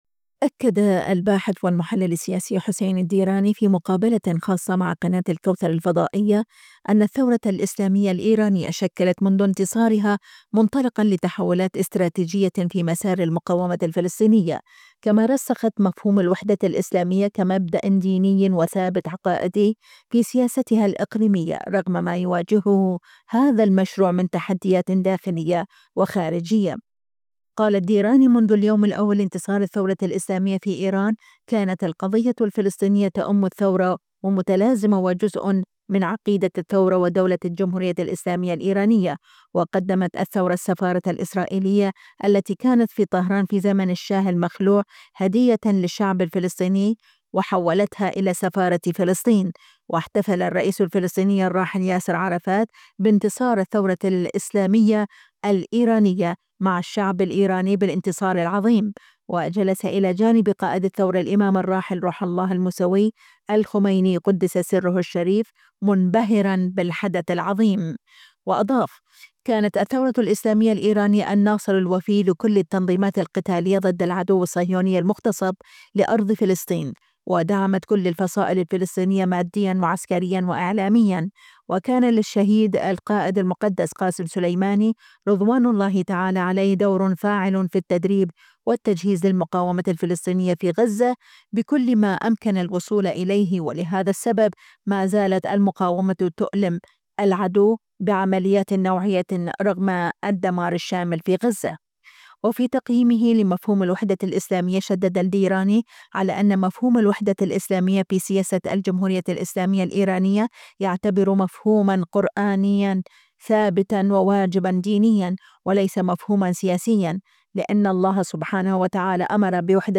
في مقابلة مع قناة الكوثر..
خاص الكوثر - مقابلات